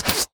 poly_shoot_arrow.wav